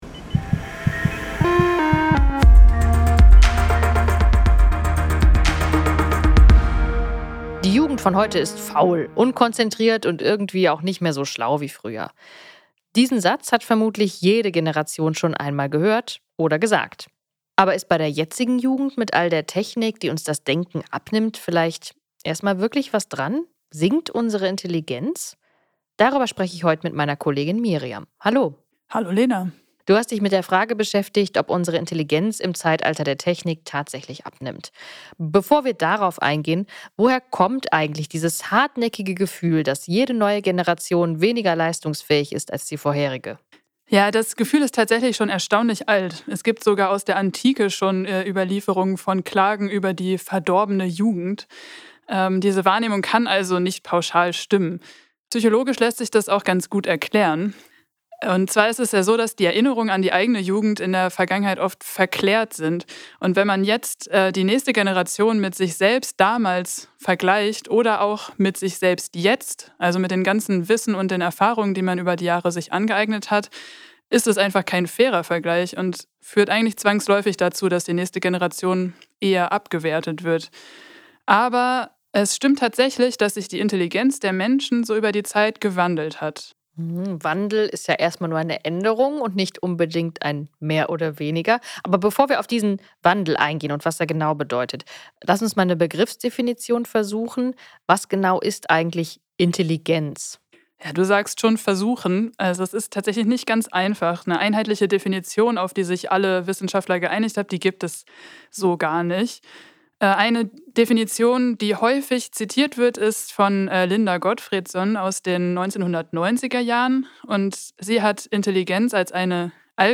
Ein Gespräch über